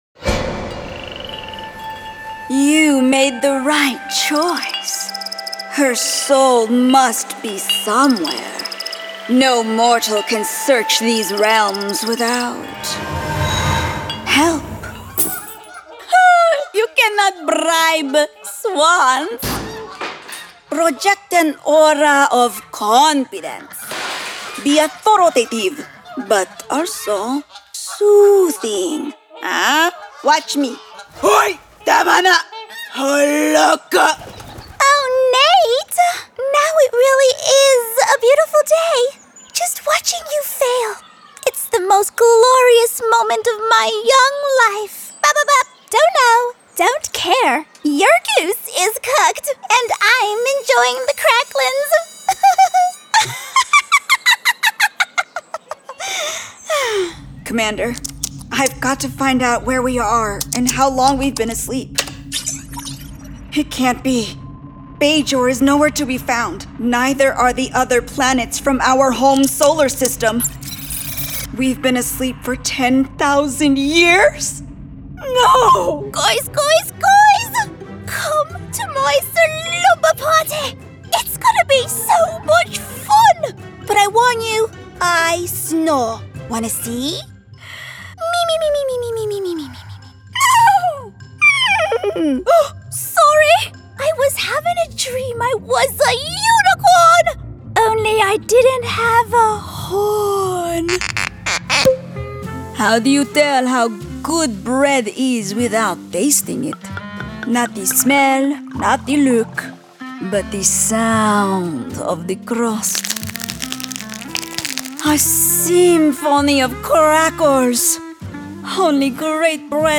Animation & Character Reel